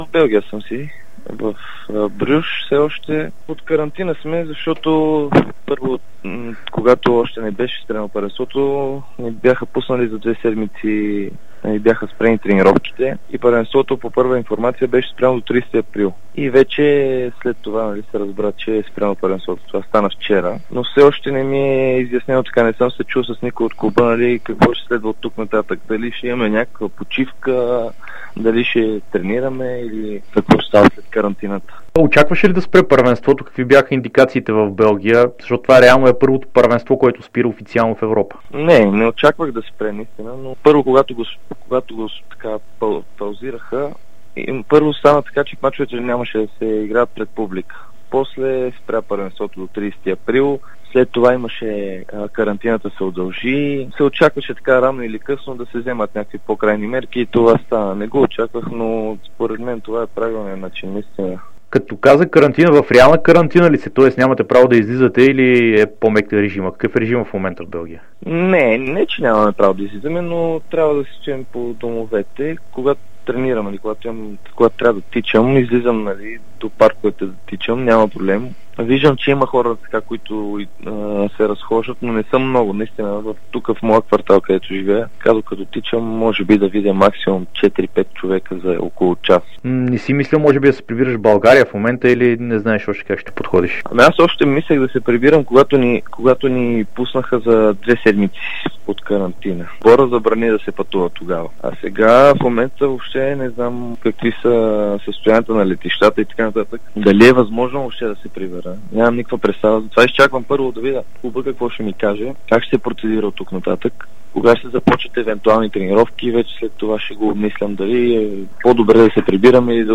Той даде интервю за dsport и Спортното шоу на Дарик, в което сподели за ситуацията в страната и в частност в неговия клуб.